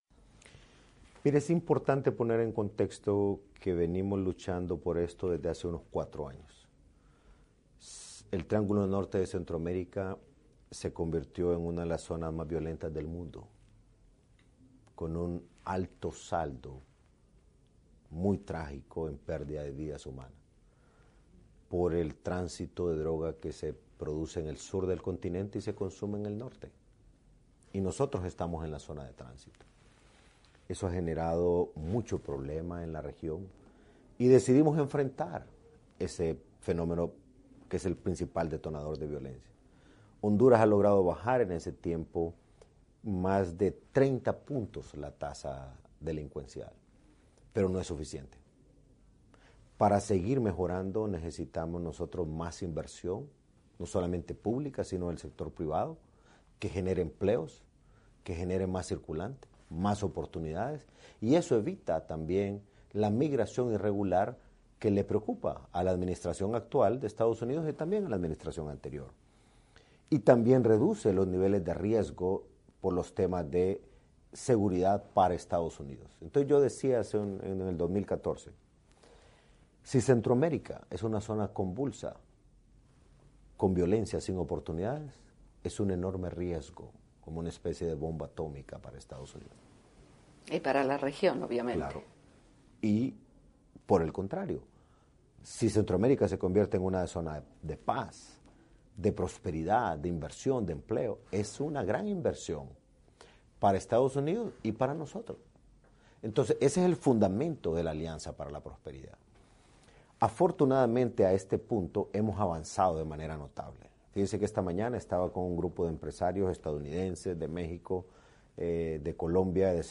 En una entrevista con la Voz de América, Hernández habló sobre el impacto que “la violencia y la falta de oportunidades” en la región tiene para el país norteamericano.